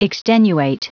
Prononciation du mot extenuate en anglais (fichier audio)
Prononciation du mot : extenuate